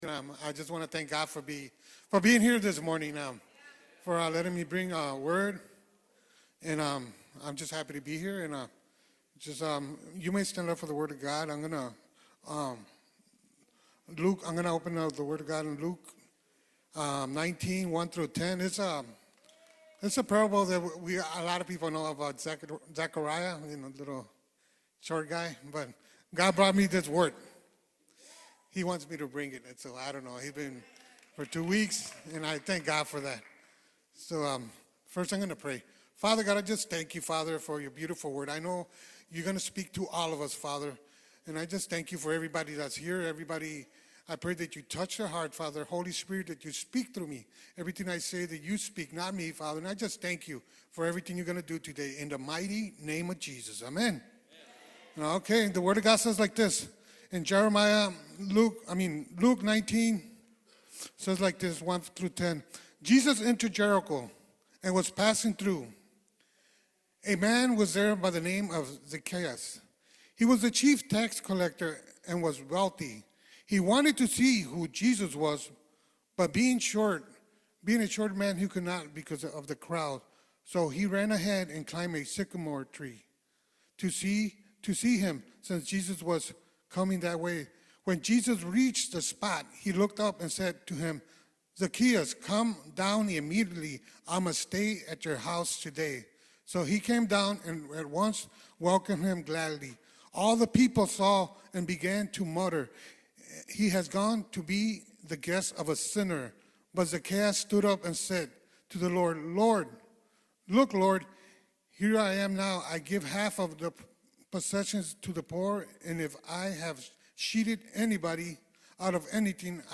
Luke 19:1-10 Service Type: Main Service You need to have a personal relationship with Jesus.